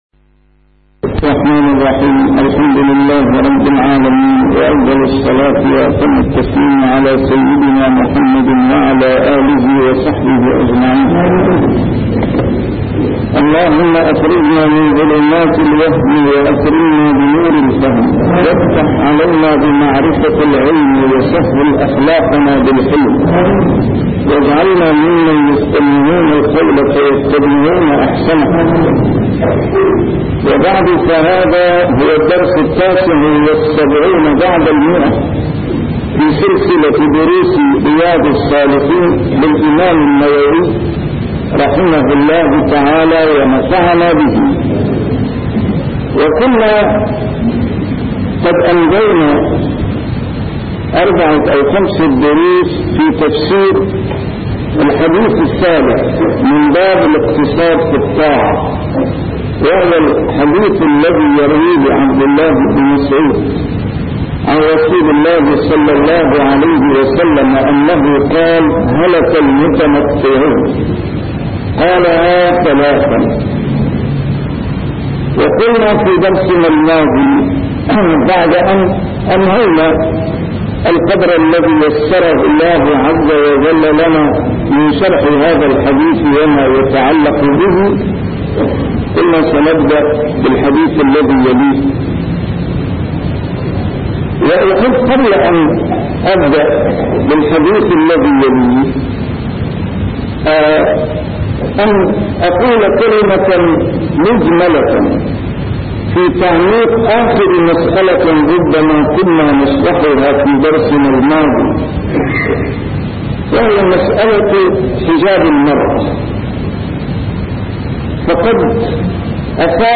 A MARTYR SCHOLAR: IMAM MUHAMMAD SAEED RAMADAN AL-BOUTI - الدروس العلمية - شرح كتاب رياض الصالحين - 179- شرح رياض الصالحين: الاقتصاد في العبادة